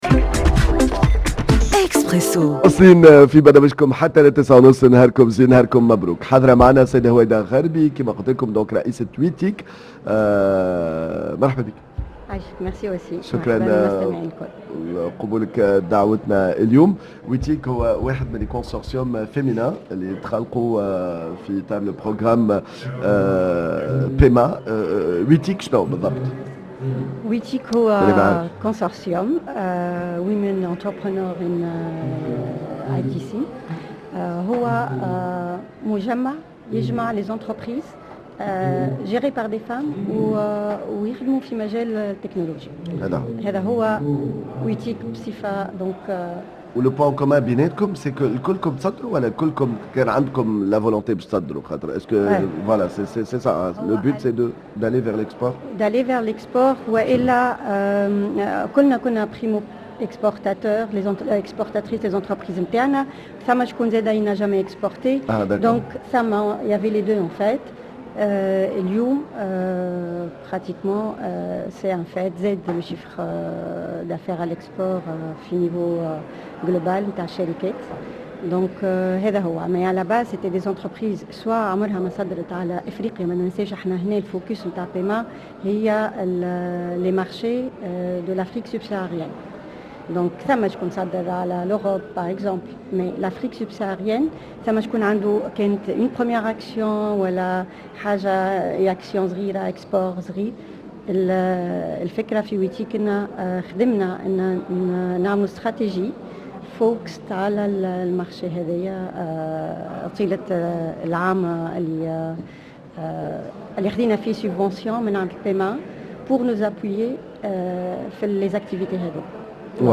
dans un plateau spécial en direct du Tunisia Export – Centre de Promotion des Exportations CEPEX